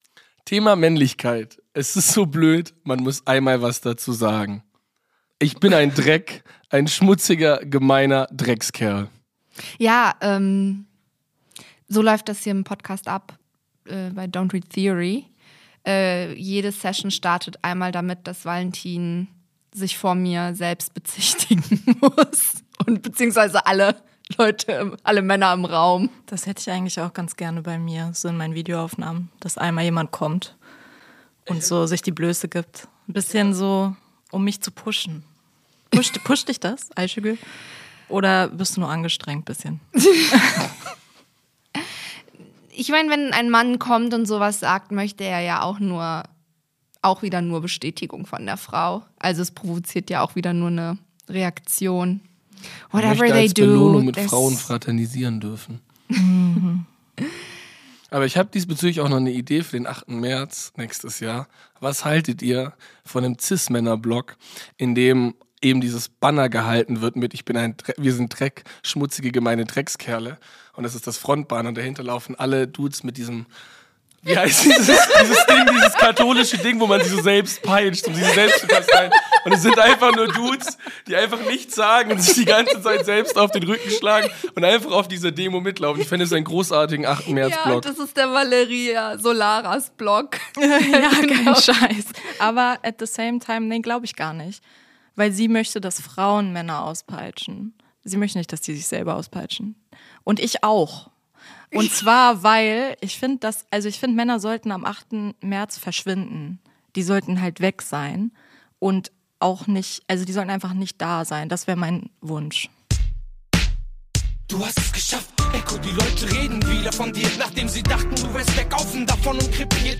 Außerdem ist Folge 005 die erste Veröffentlichung, die wir gemeinsam mit Kreuzbergs stabilstem Audioproduktionsteam Studio SOMA aufgenommen haben und dementsprechend ist alles ab jetzt Premiumqualität!